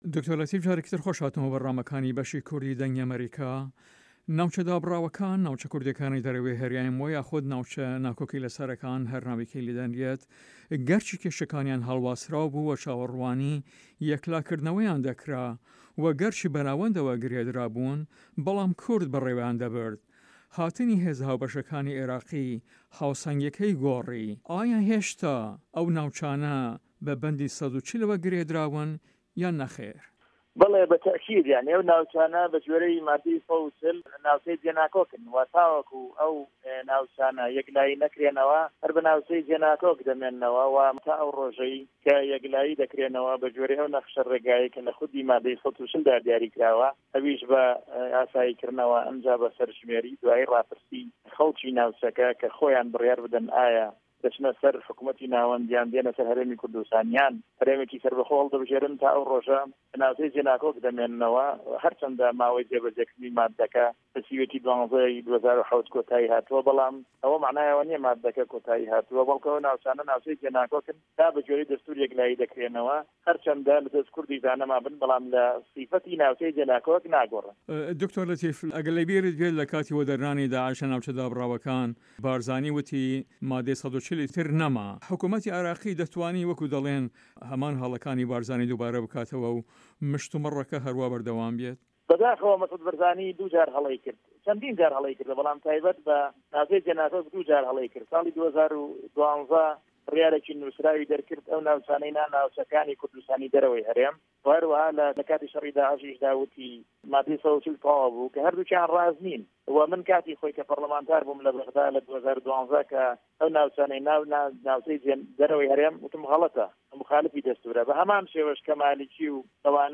Interview with Dr. Latif Mustafa